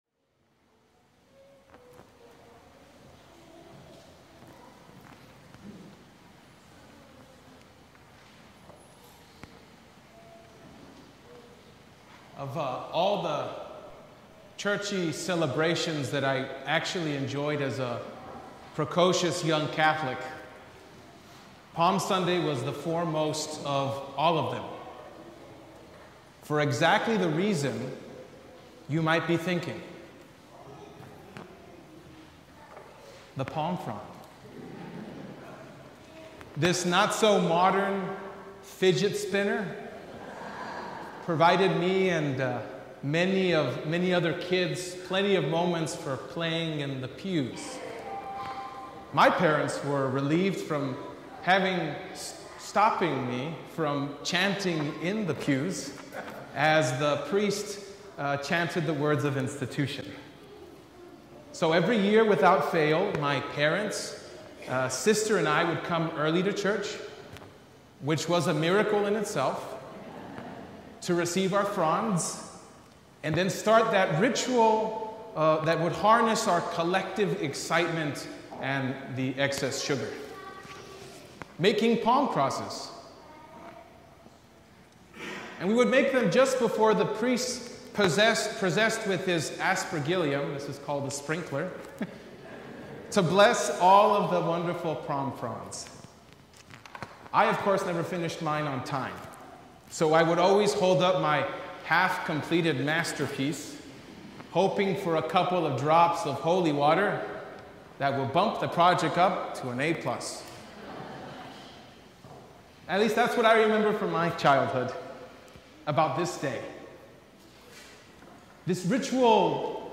Sermon from Palm Sunday